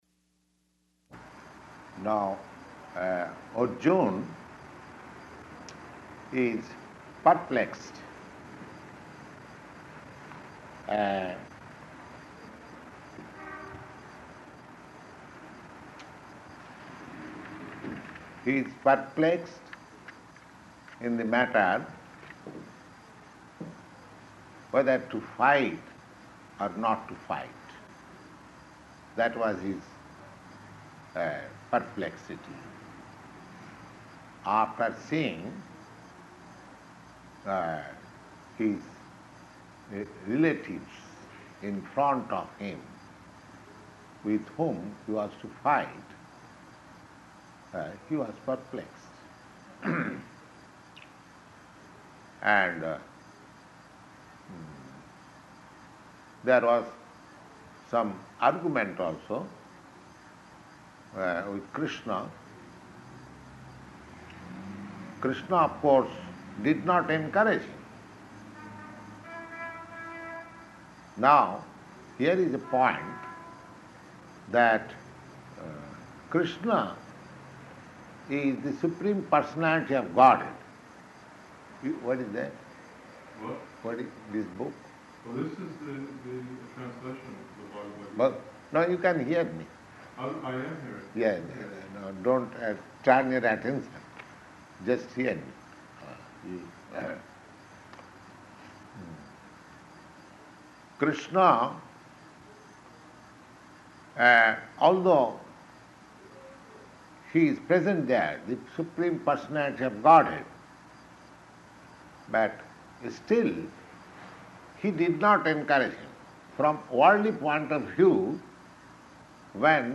Bhagavad-gītā 2.7–11 --:-- --:-- Type: Bhagavad-gita Dated: March 2nd 1966 Audio file: 660302BG-NEW_YORK.mp3 [Now I am confused about my duty and have lost all composure because of weakness.